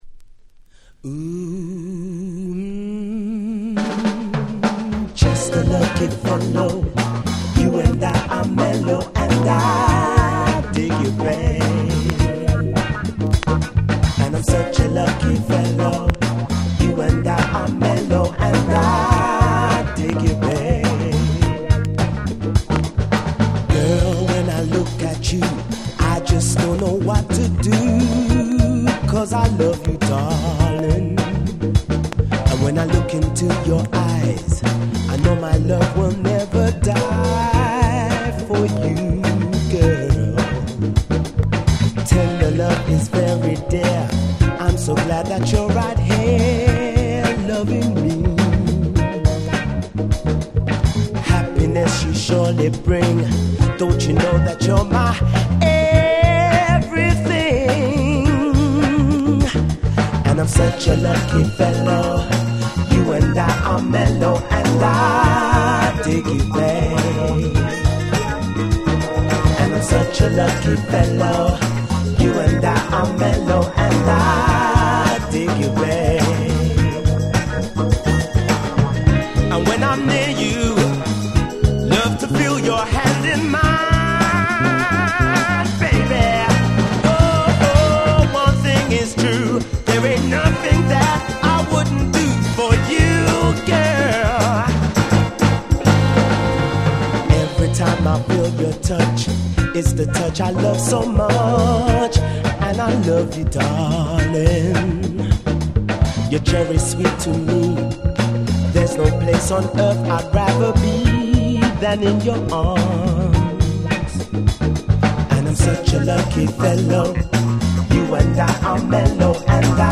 92' Super Nice UK Soul / Acid Jazz !!